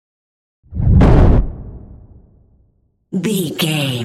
Dramatic deep whoosh to hit trailer
Sound Effects
Atonal
dark
intense
tension
woosh to hit